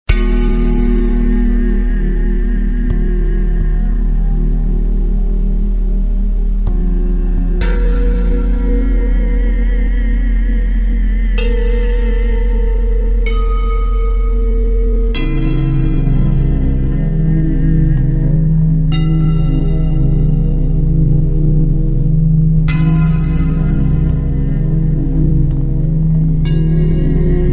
These 7 ancient Solfeggio tones sound effects free download